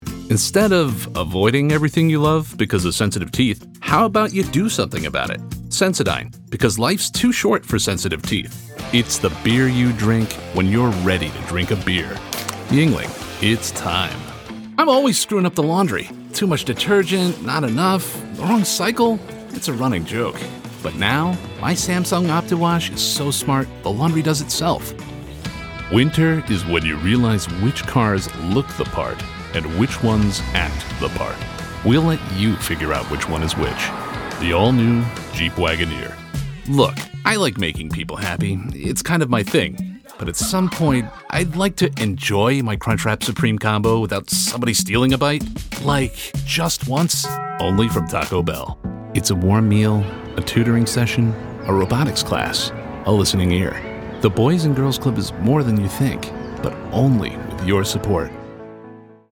Young Adult, Adult, Mature Adult
Has Own Studio
COMMERCIAL 💸
conversational
gravitas
husky